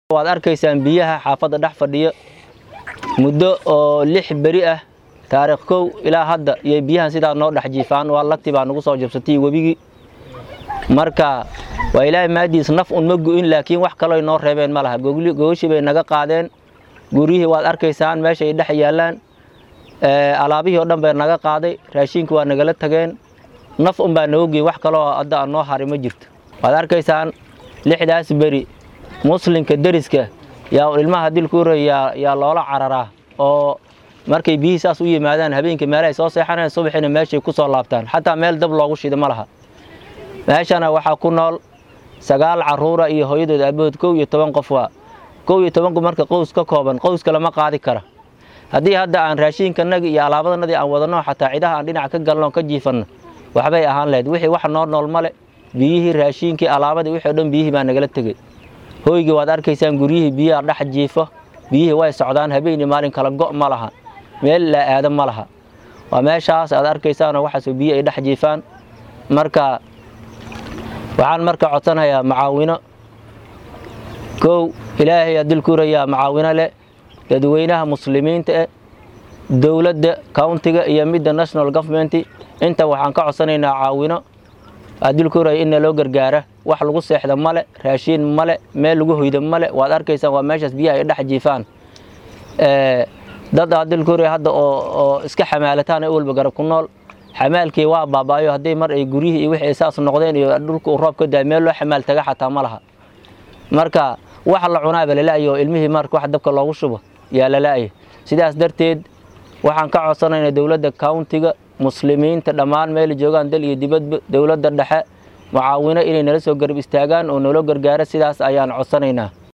Xubnaha qoyskan oo la daala dhacaya xaaladda adag ee soo foodsaartay ayaa dareenkooda sidatan ula wadaagay warbaahinta Star.